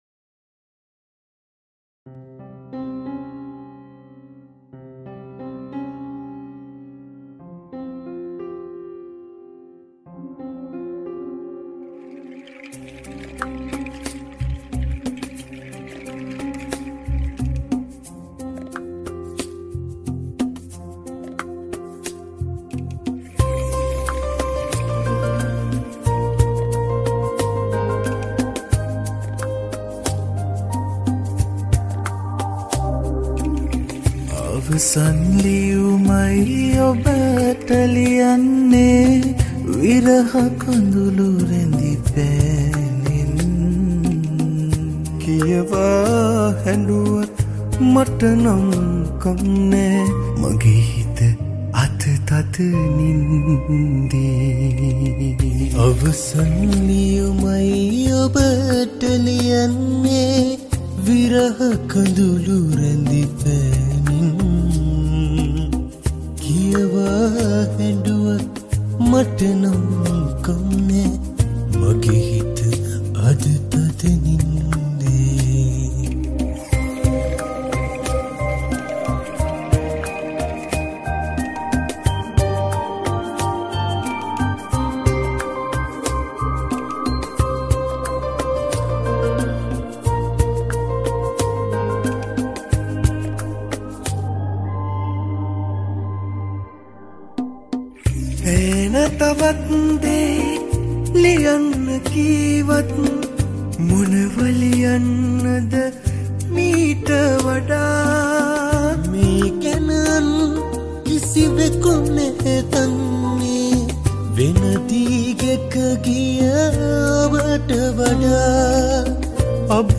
Covers